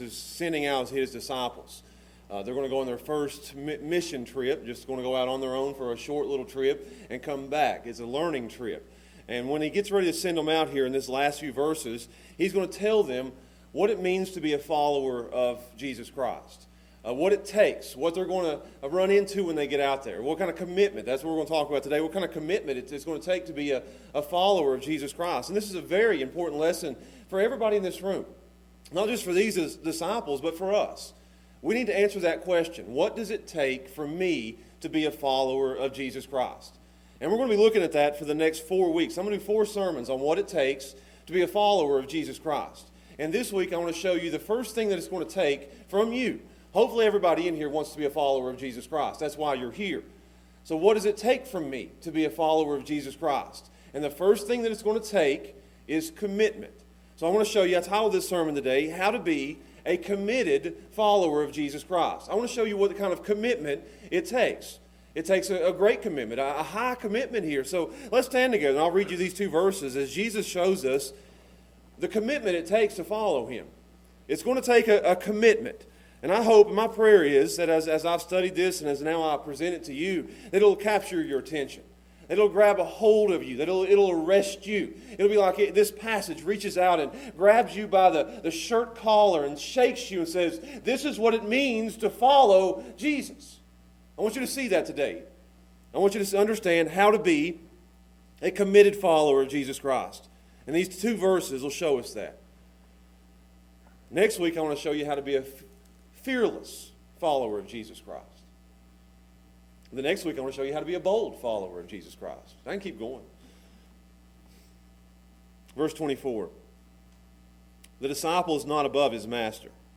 Committed Follower | SermonAudio Broadcaster is Live View the Live Stream Share this sermon Disabled by adblocker Copy URL Copied!